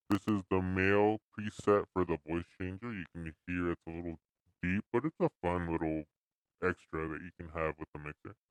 The SC3 comes with a voice changer feature.
Male Preset
malevoicechange.mp3